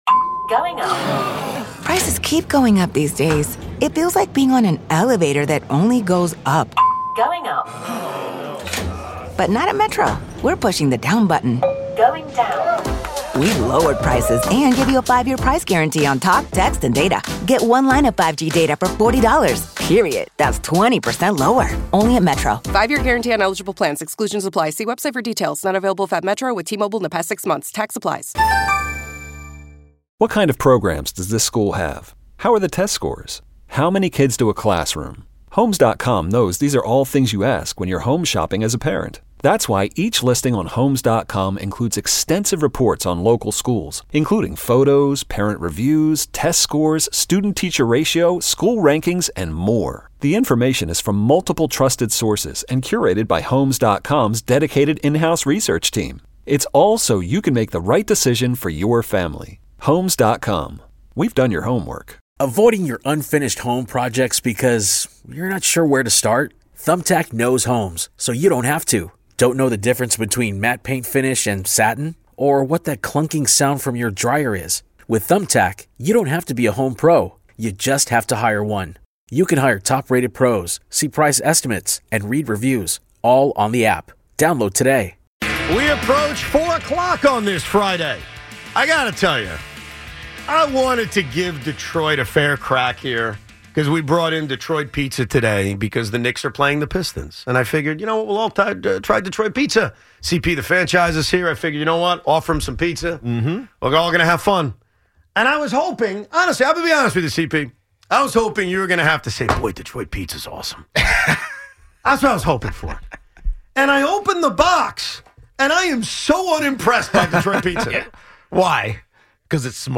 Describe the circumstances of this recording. joins us in studio to look ahead to the Knicks first round